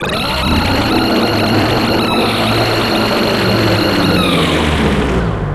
LavosScream.wav